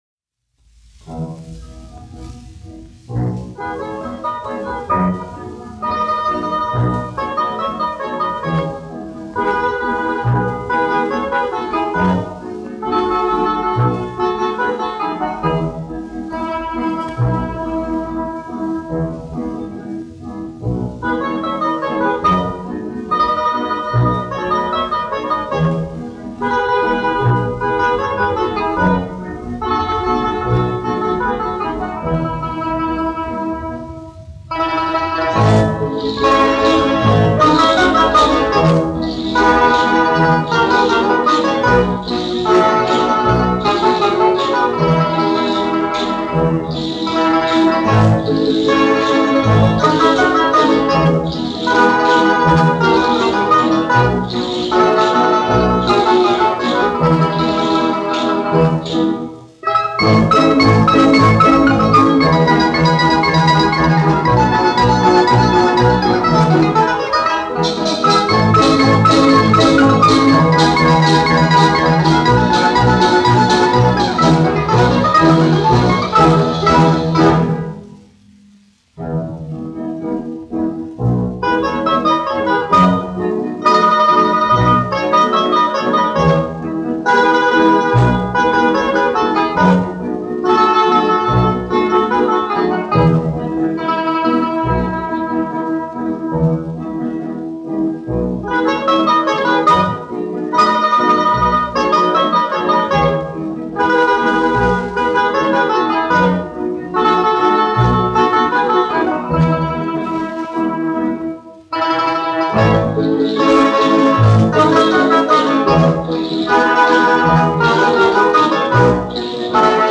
1 skpl. : analogs, 78 apgr/min, mono ; 25 cm
Balalaiku orķestra mūzika, aranžējumi
Skaņuplate